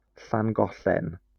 Llangollen (Welsh: [ɬaŋˈɡɔɬɛn]